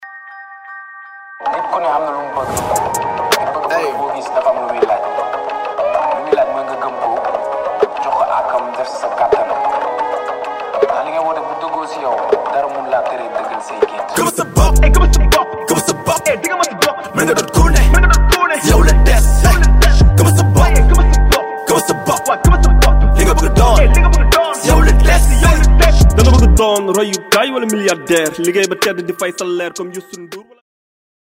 Un album 100% Rap mêlant émotions, poésie et mélodies